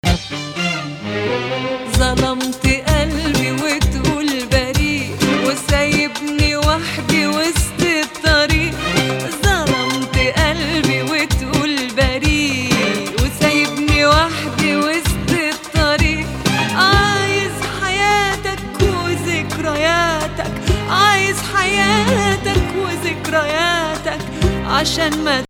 Nahawand 4
reaches flat 6 above Nah.; raised 4 ornament typical